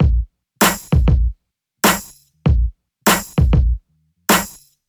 • 98 Bpm HQ Rap Drum Loop F Key.wav
Free drum loop - kick tuned to the F note. Loudest frequency: 1983Hz
98-bpm-hq-rap-drum-loop-f-key-uzD.wav